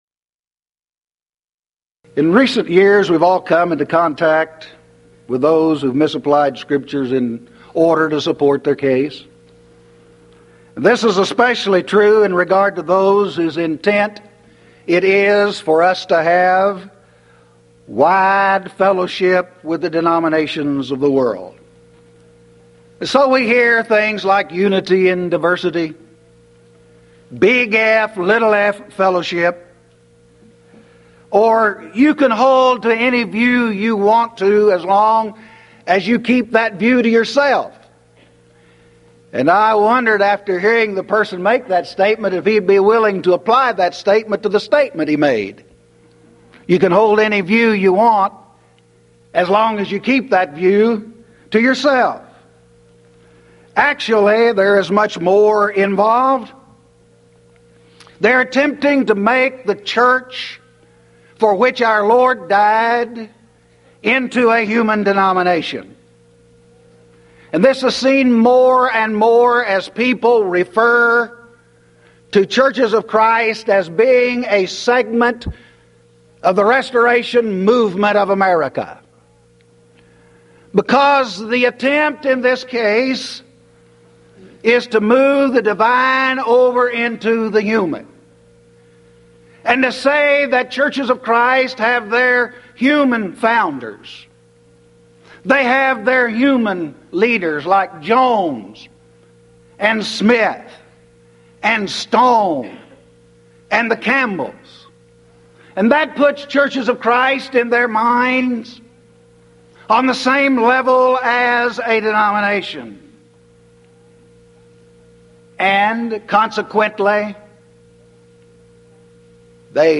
Event: 1995 Mid-West Lectures
this lecture